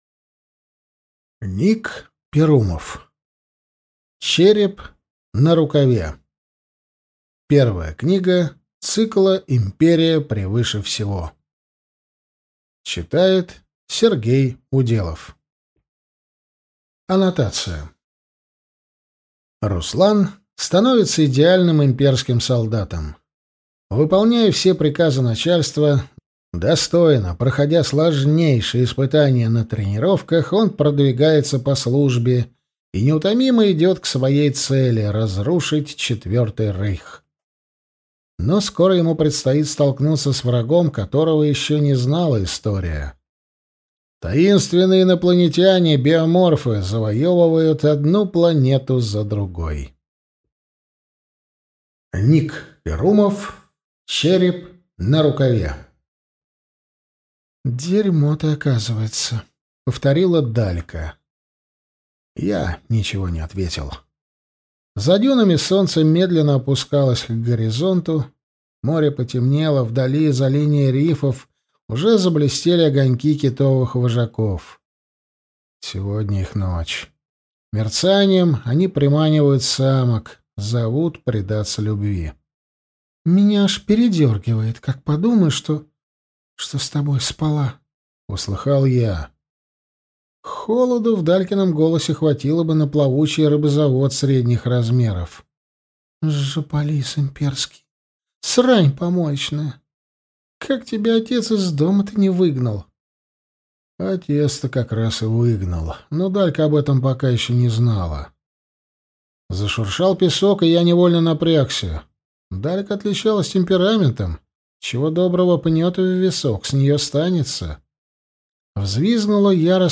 Аудиокнига Череп на рукаве | Библиотека аудиокниг